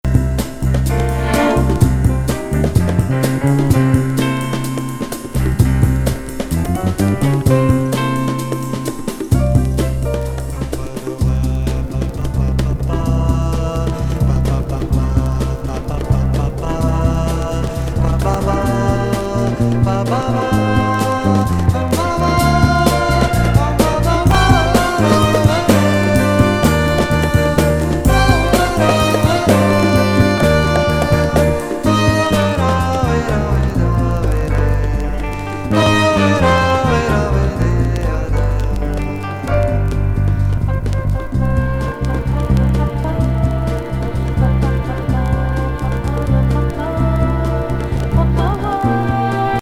ボッサMPBをサイケデリック化させたA1